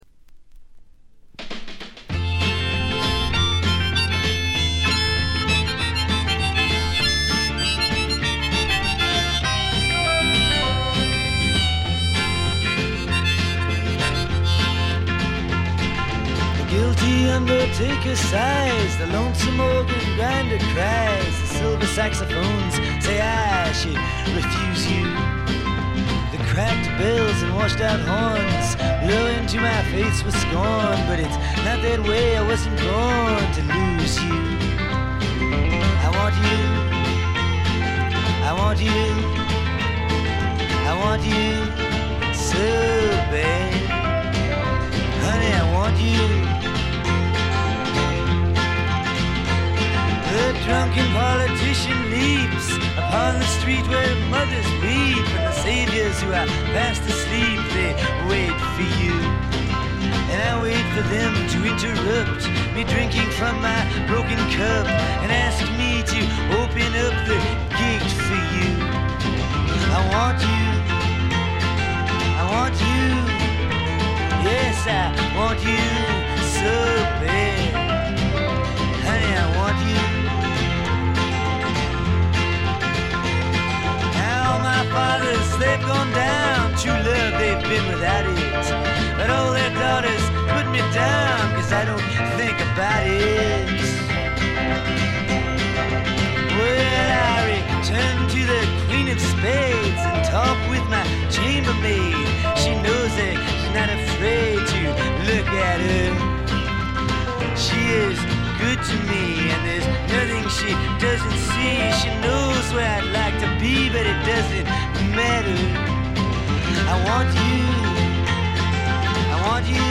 A面最後フェードアウト終了間際からとB1中盤で少々チリプチ。
試聴曲は現品からの取り込み音源です。
vocals, guitar, harmonica, piano